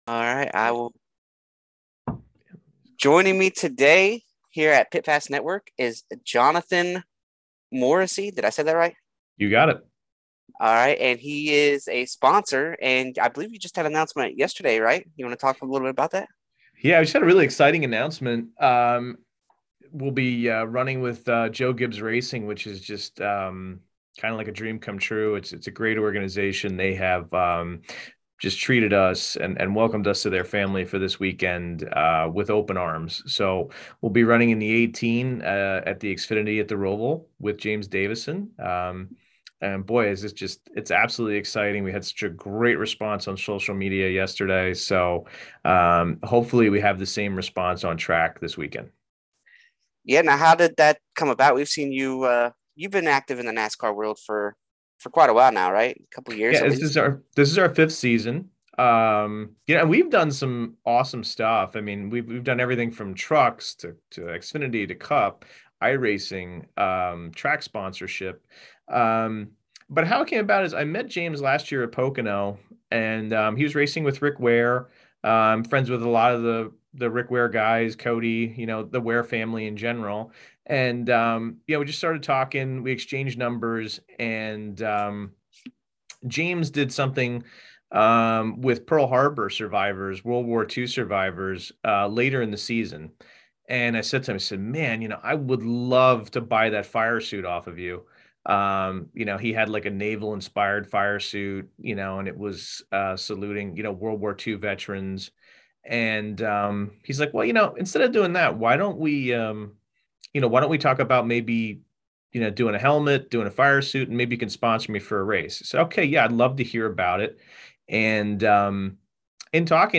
Pit Pass Network Interview: Rich Mar Florist - Pit Pass Network